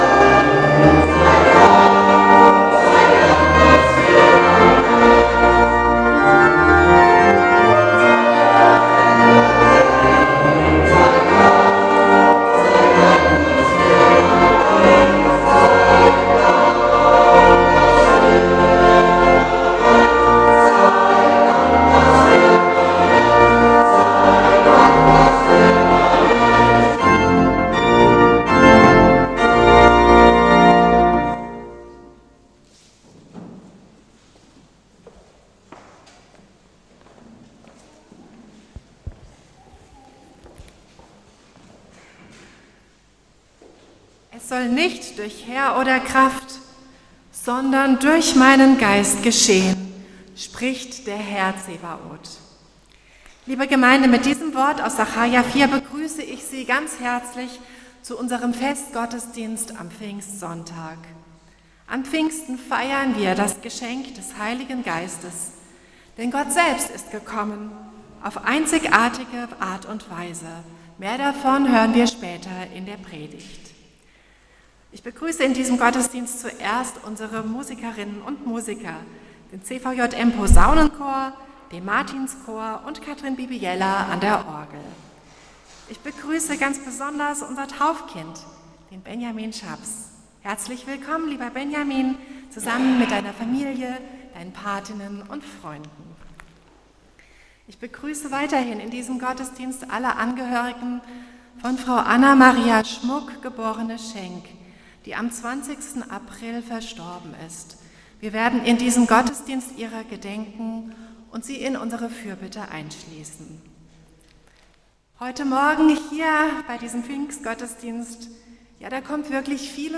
Pfingstgottesdienst vom 8.6.2025 als Audio-Podcast Liebe Gemeinde, herzliche Einladung zum Pfingstgottesdienst mit Taufe in der Martinskirche Nierstein als Audio-Podcast. Musikalisch wird der Gottesdienst begleitet vom Martinschor und vom CVJM-Posaunenchor.